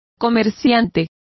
Complete with pronunciation of the translation of tradesman.